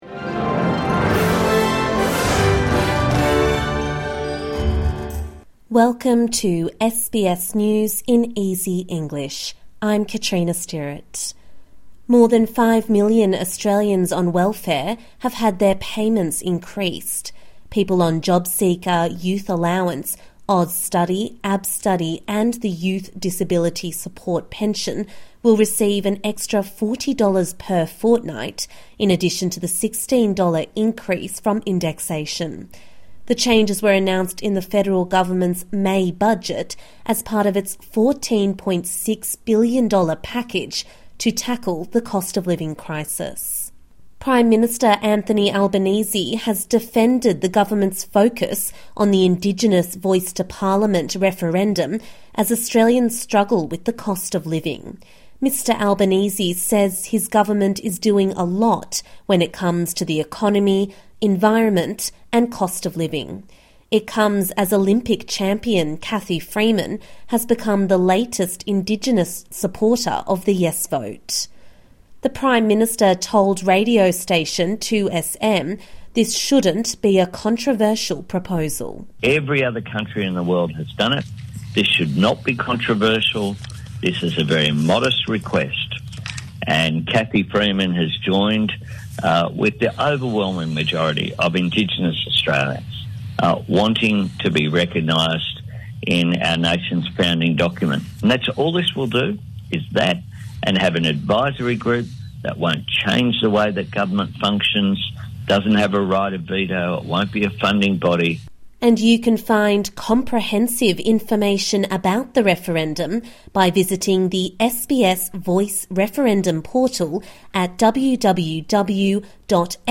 A daily 5 minute news wrap for English learners and people with disability.